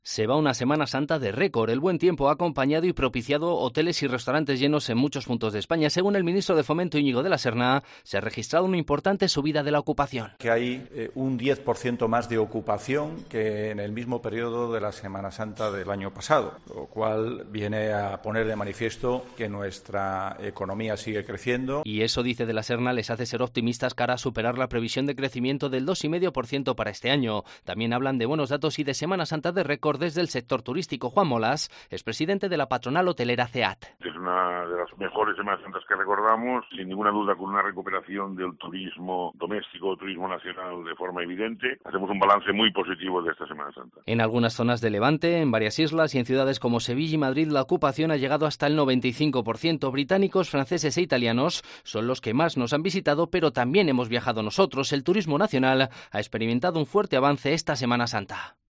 Escucha toda la información con